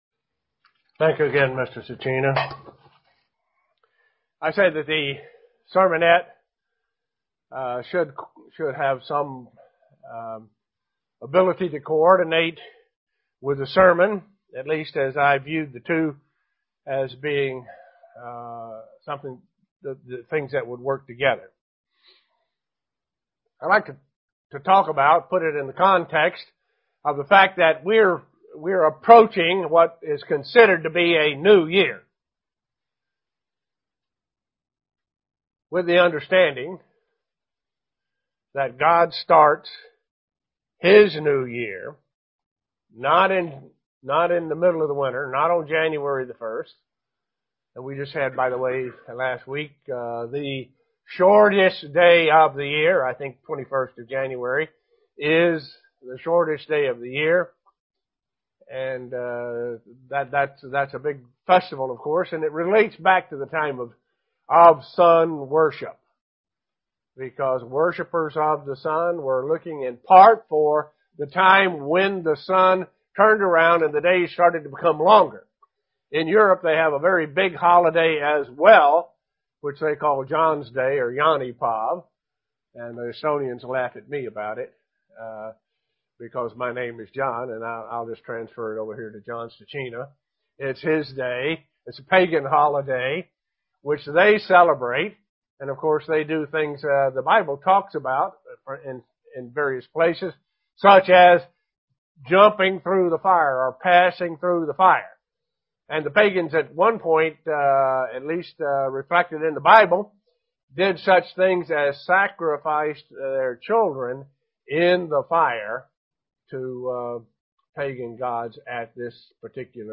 Given in Elmira, NY
Print Five themes in the last year that relates to prophecy UCG Sermon Studying the bible?